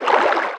Sfx_creature_penguin_idlesea_A_01.ogg